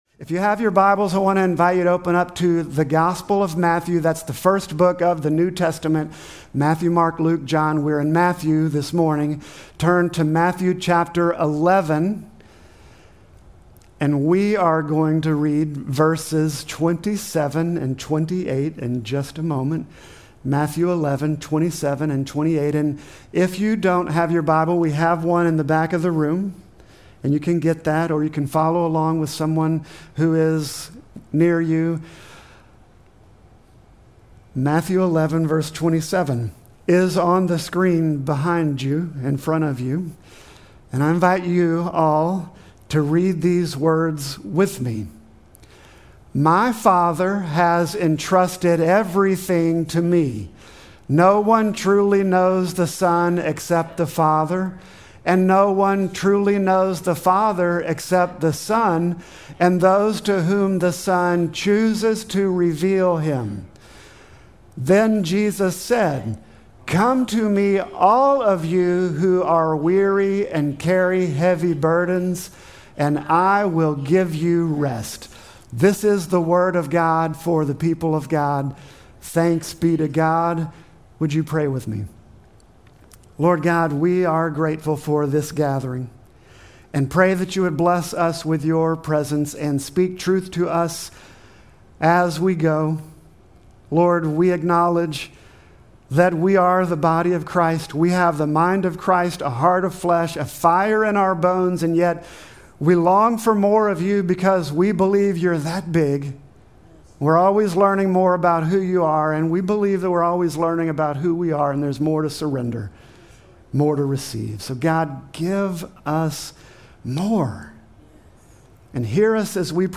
Sermon text: Matthew 11:27-28